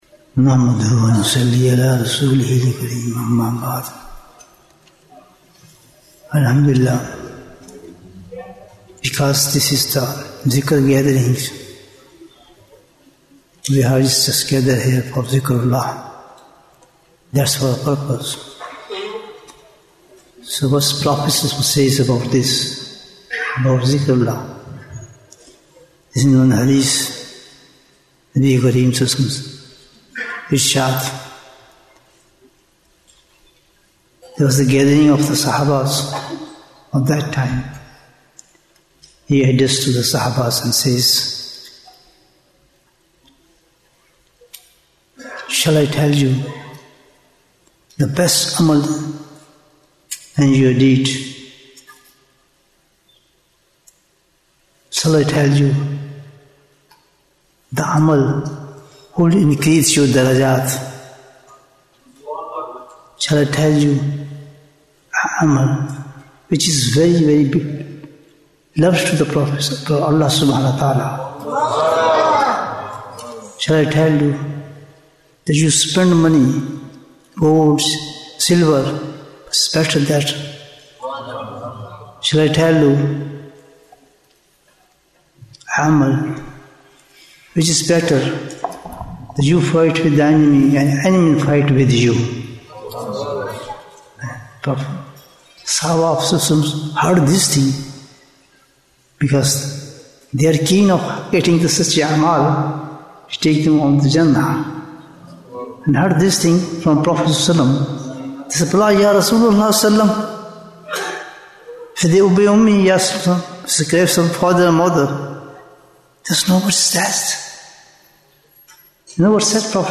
Bayan Annual Ijtema 2025